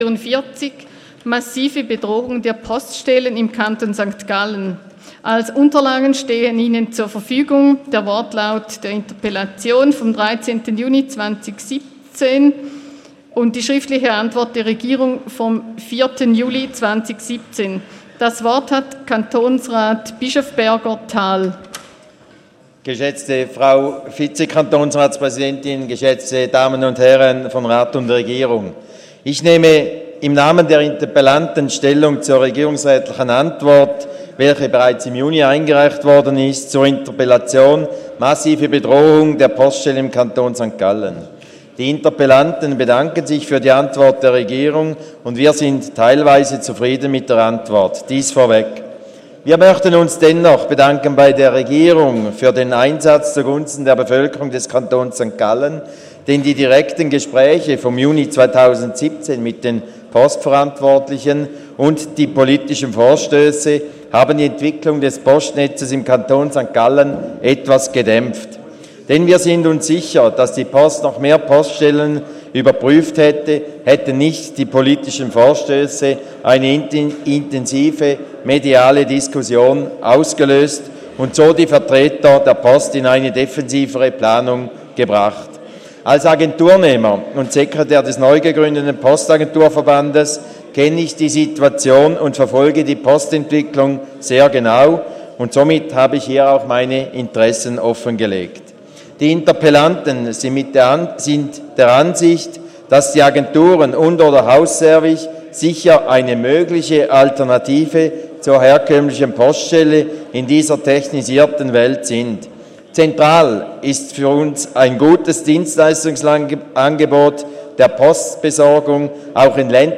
28.11.2017Wortmeldung
Session des Kantonsrates vom 27. und 28. November 2017